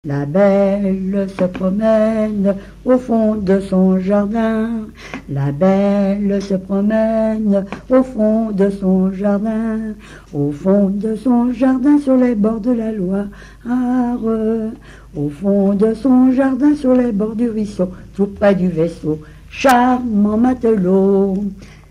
Mémoires et Patrimoines vivants - RaddO est une base de données d'archives iconographiques et sonores.
chanteur(s), chant, chanson, chansonnette
Genre laisse
Catégorie Pièce musicale inédite